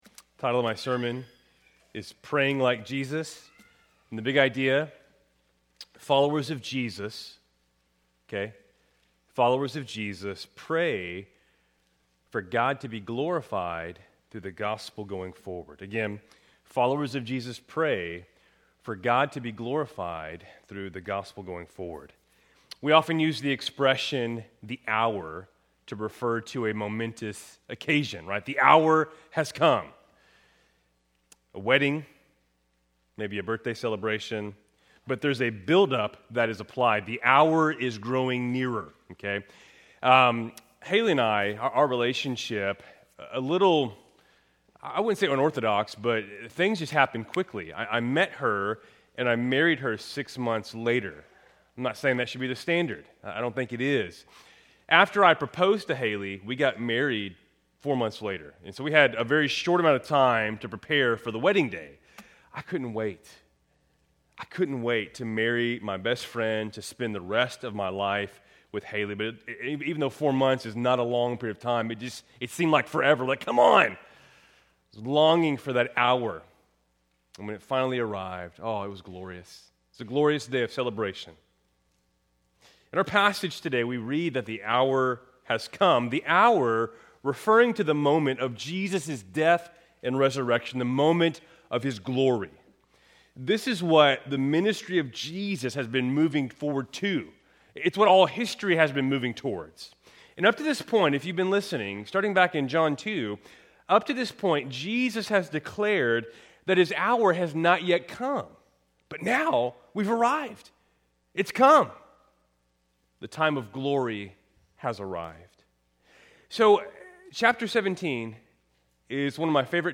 Keltys Worship Service, November 9, 2025